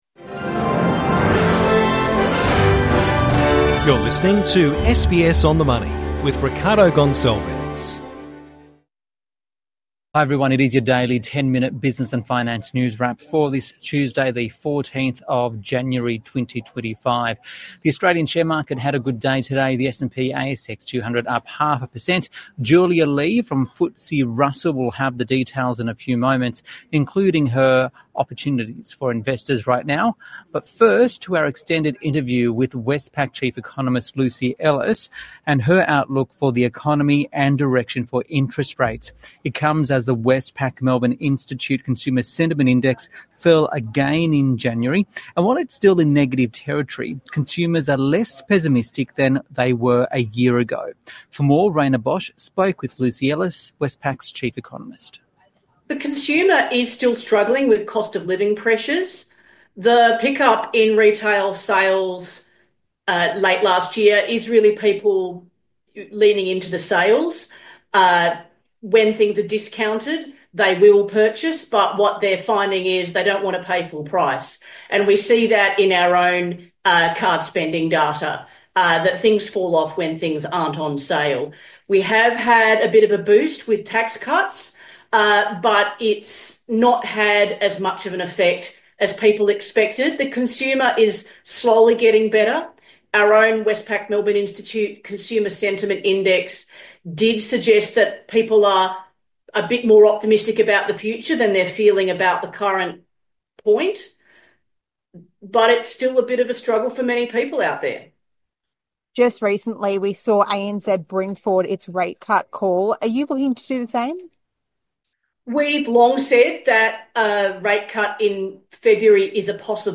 Economist interview